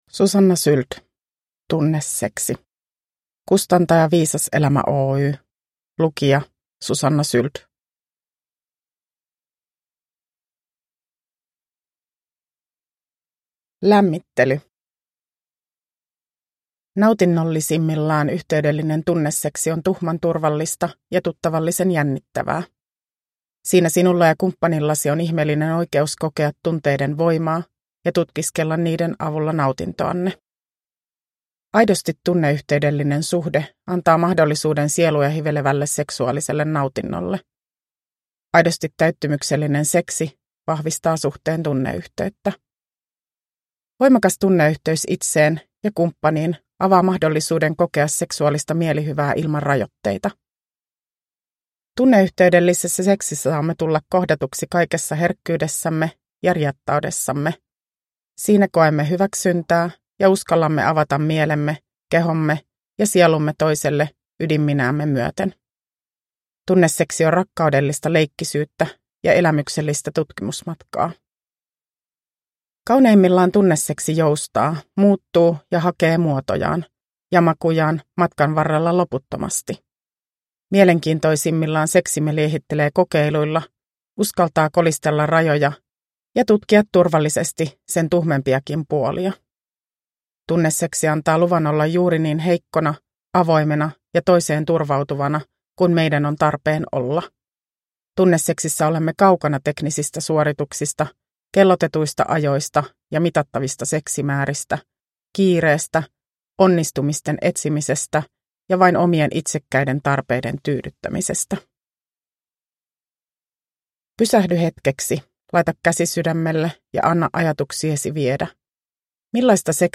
Tunneseksi – Ljudbok